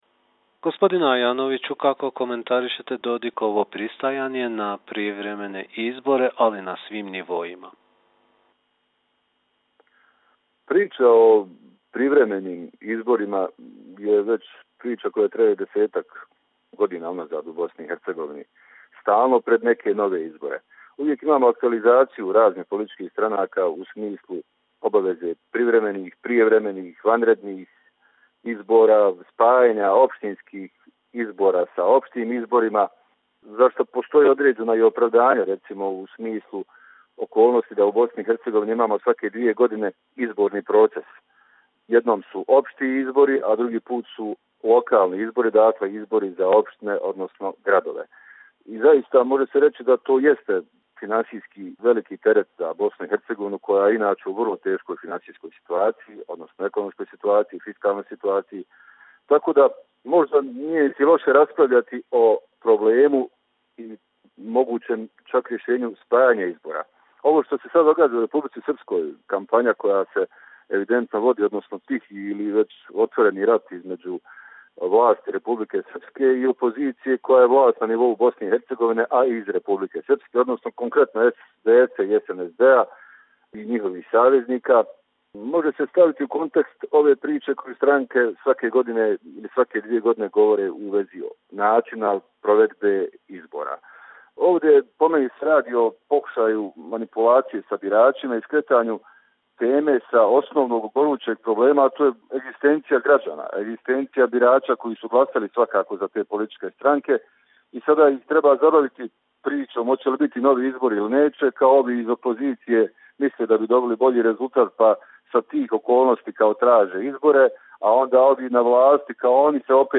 Tonske izjave